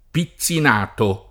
[ pi ZZ in # to ]